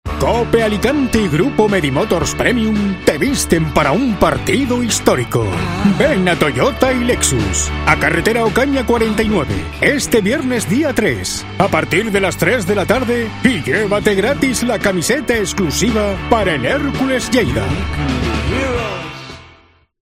Promo camisetas conmemorativas Hércules - Lleida